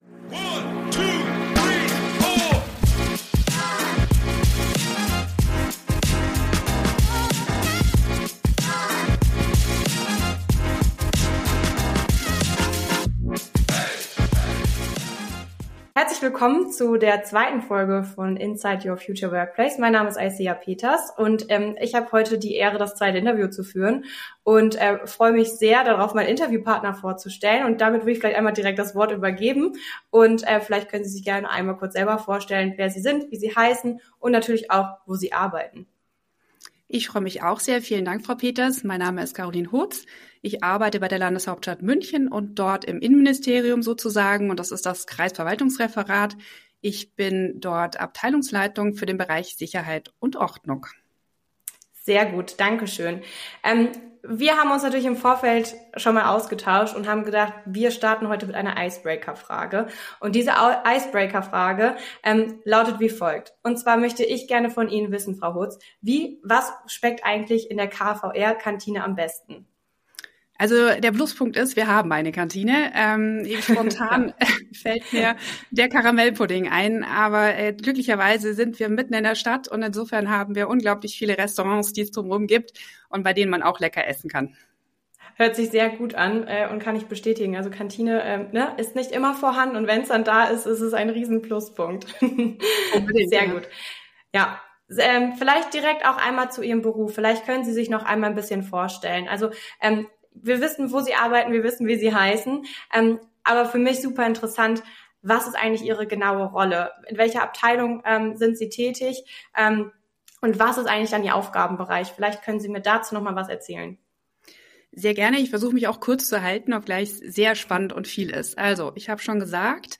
Lerne durch kurzweilige Interviews spannende juristische Arbeitgeber und Tätigkeitsbereiche kennen. In diesem Podcast stellen wir Dir regelmäßig verschiedene Arbeitgeber aus der Kanzlei- und Unternehmenswelt sowie aus dem öffentlichen Dienst vor.